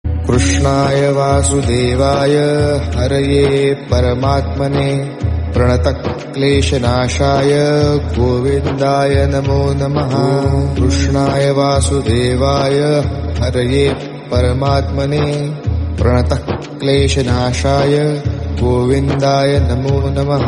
Bhakti Ringtones